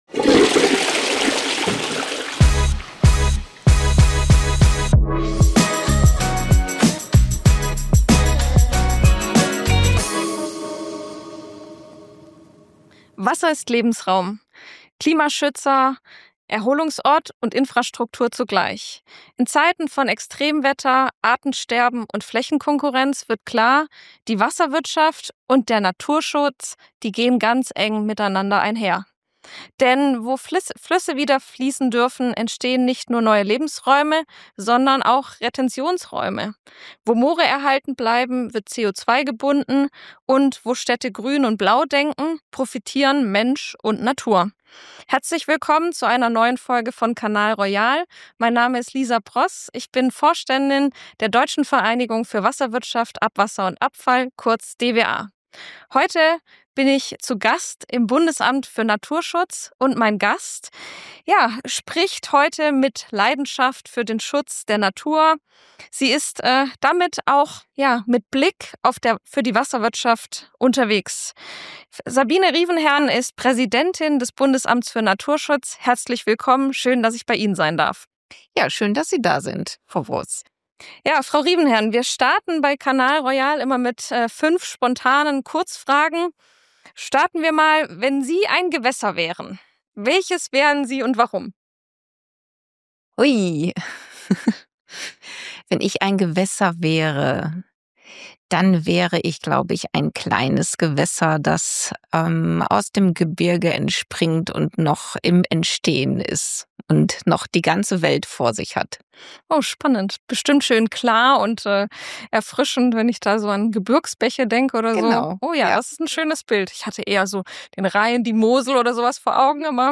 im Gespräch mit der obersten Naturschützerin Deutschlands